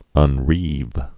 (ŭn-rēv)